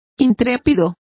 Complete with pronunciation of the translation of dauntless.